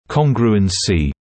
[‘kɔŋgruəns][‘конгруэнси]конгруэнтность (также congruence)